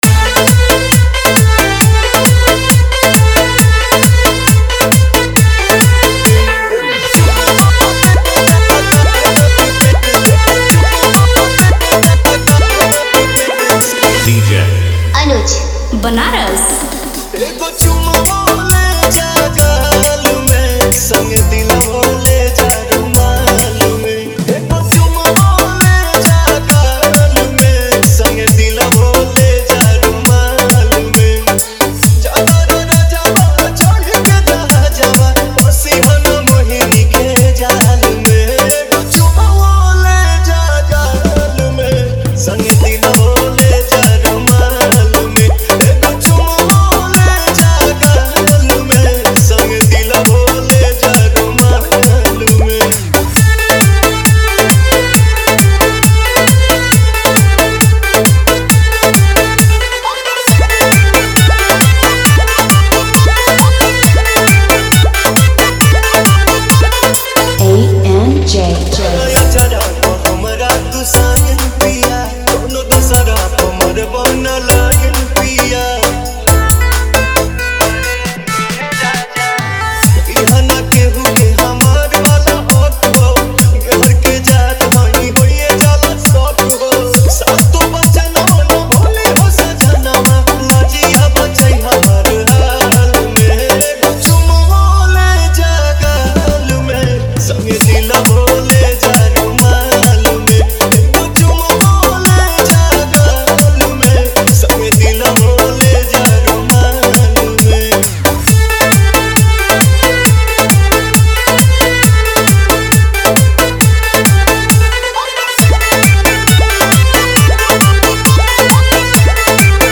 आवाज़: फेमस भोजपुरी सिंगर
कैटेगरी: डीजे डांस मिक्स, देसी रोमांटिक सांग